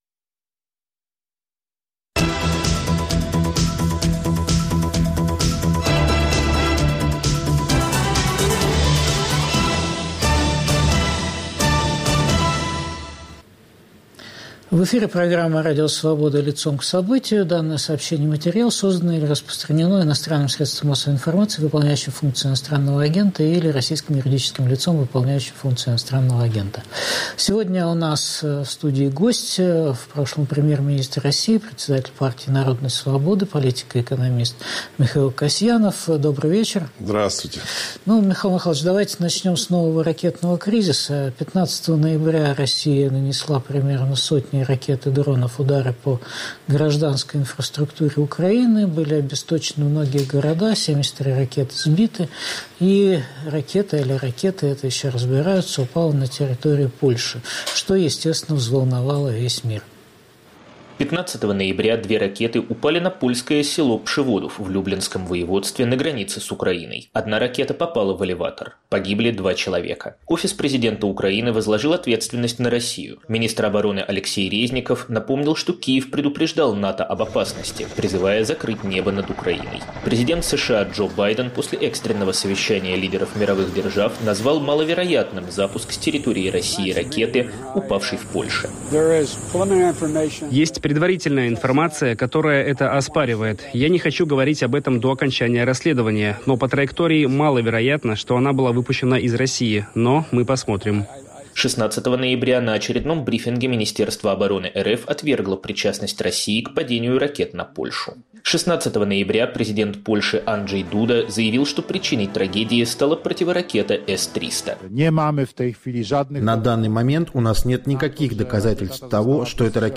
Гость программы "Лицом к событию" политик и экономист Михаил Касьянов.